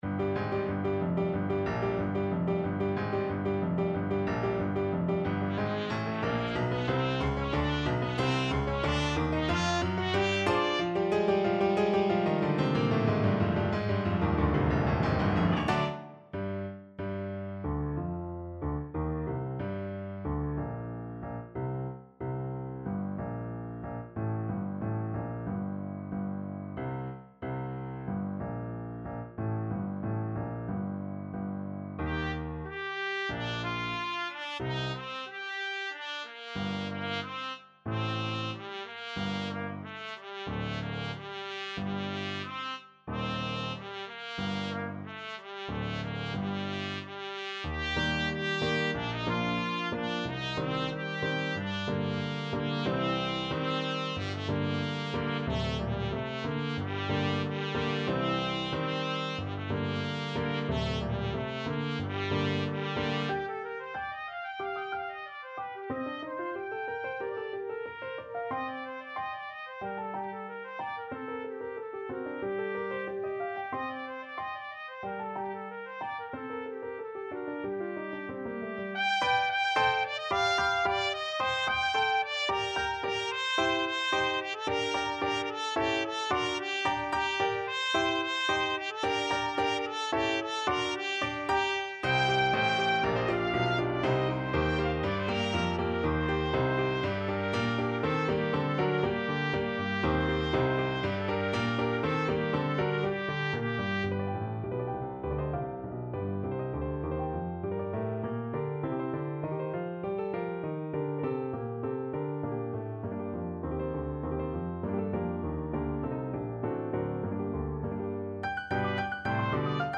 Trumpet version
2/4 (View more 2/4 Music)
Allegro =92 (View more music marked Allegro)
G4-Ab6
Trumpet  (View more Advanced Trumpet Music)
Classical (View more Classical Trumpet Music)